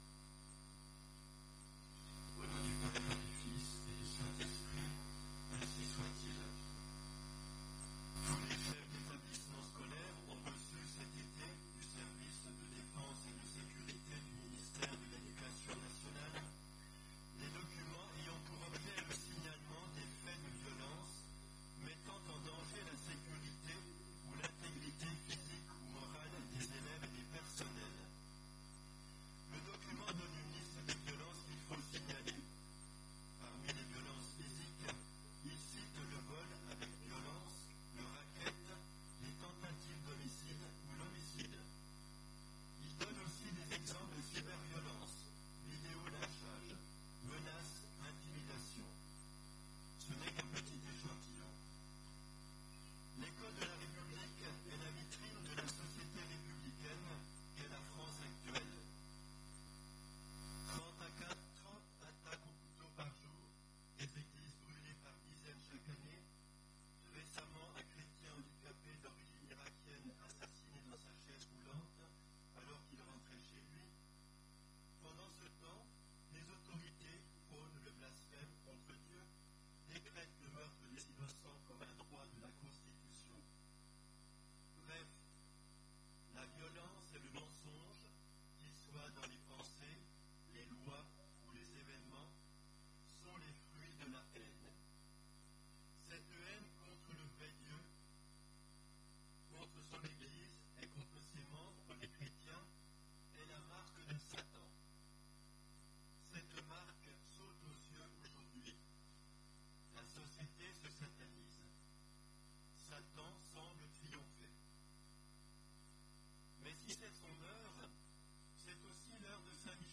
Type: Sermons Occasion: Fête de Saint Michel